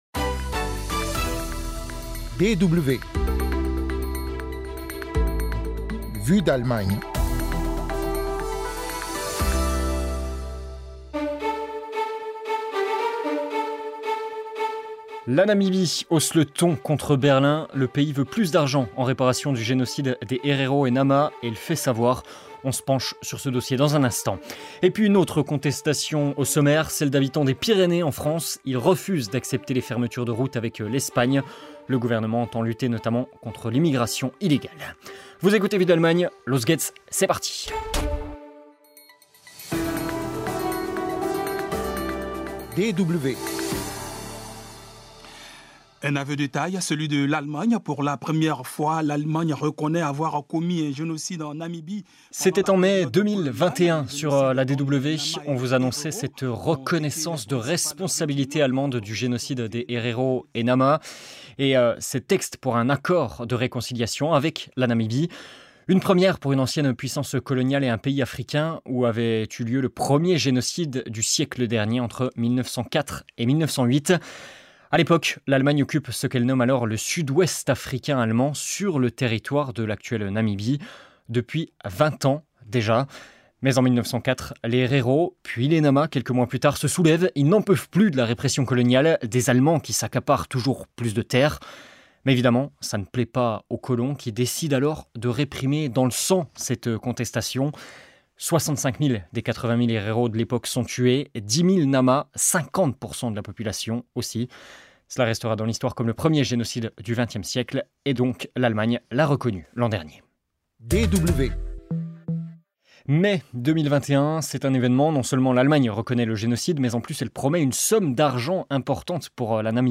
Après l'annonce d'un accord financier et la reconnaissance du génocide contre les Hereros et les Namas il y a un an, Windhoek réclame désormais davantage d'argent à l'Allemagne. En seconde partie de cette émission : reportage à la frontière franco-espagnole, où des habitants combattent la fermeture de routes par le gouvernement français qui entend lutter notamment contre l'immigration illégale.